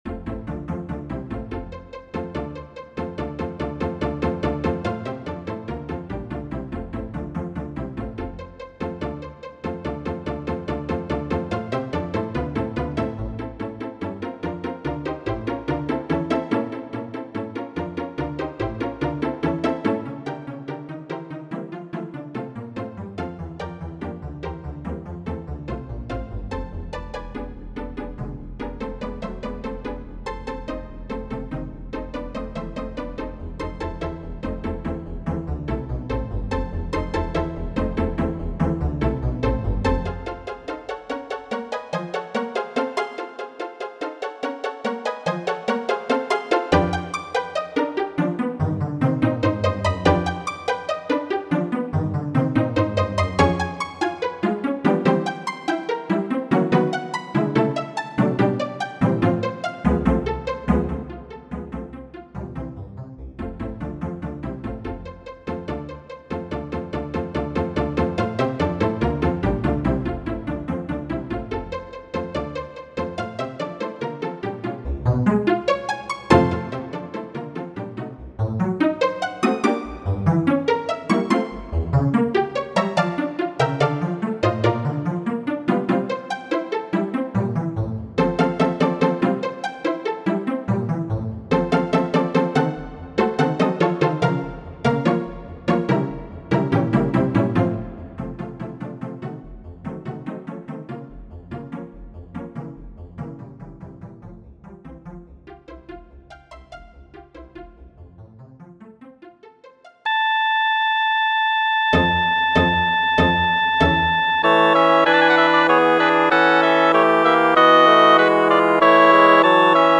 Pinĉato, tria parto de la 4-a simfonio de Petro Ludoviko Ĉejkovski, verko 64-a.